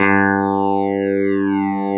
Index of /90_sSampleCDs/USB Soundscan vol.09 - Keyboards Old School [AKAI] 1CD/Partition B/04-CLAVINET3
CLAVI3  G2.wav